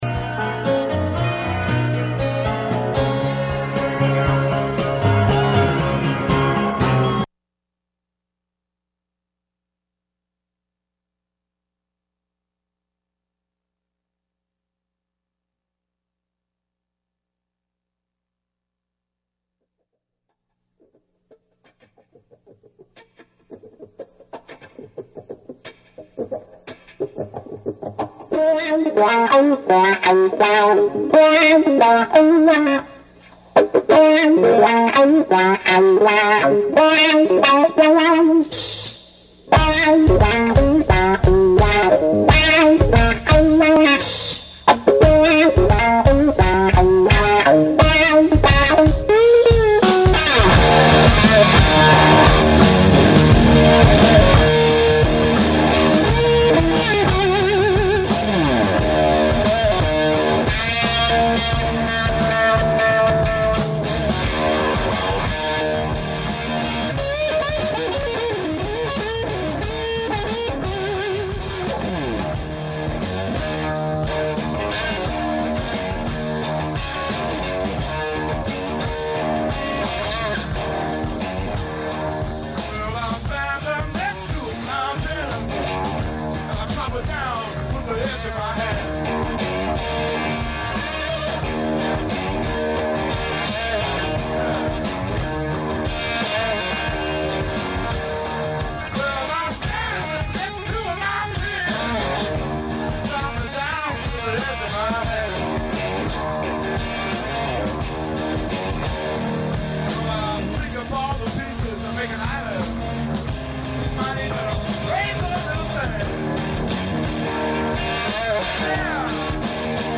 On this show, you’ll hear recent news, personal experiences, and a diverse selection of music. Youth Radio Raw is a weekly radio show produced by Bay Area high schoolers, ages 14-18.